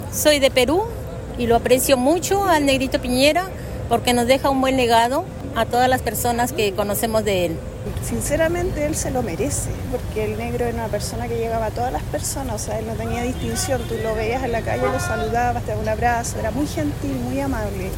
cu-mix-gente-x-negro-pinera.mp3